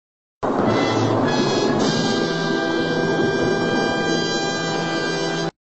Se você já ouviu um **"TAN TAN TAAAAN"** seguido de um olhar intenso, então conhece o lendário **"Dramatic Chipmunk"**, um dos primeiros memes virais da internet! Apesar do nome, o animal do vídeo não é um esquilo, mas sim um **lemingue**, que aparece virando a cabeça dramaticamente para a câmera ao som de uma **trilha sonora digna de um filme de suspense**. O vídeo, originalmente de um programa japonês, foi **postado no YouTube em 2007** e rapidamente se espalhou, sendo usado em montagens hilárias para enfatizar momentos de **choque, reviravoltas ou conspirações**.